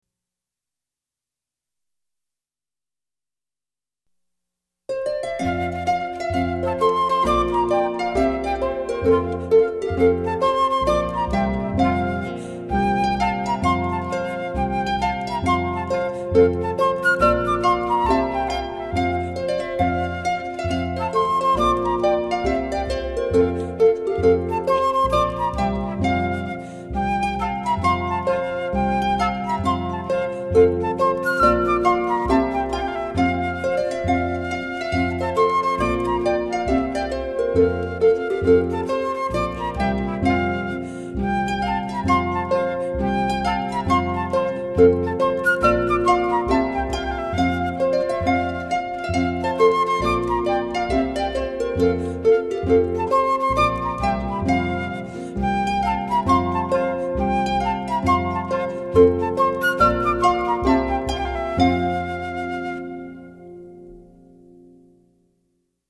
[纯音乐]
一把小提琴伴奏着，一个大大竖琴，在海边海风轻轻的吹拂下，一个长发飘逸的女人挥动着十指，抚摸着琴弦....